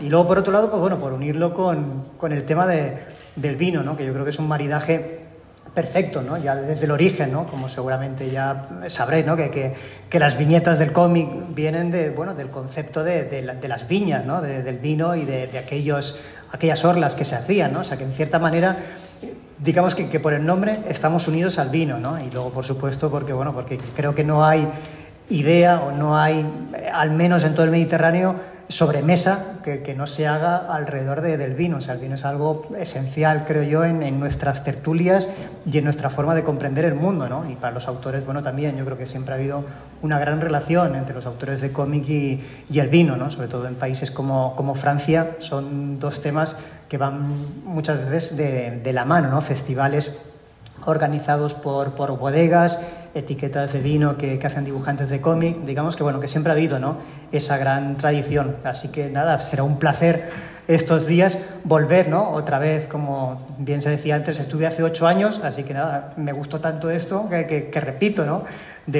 Intervención Paco Roca